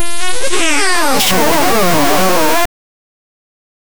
spike volleyball game
spike-volleyball-game-eva2zldz.wav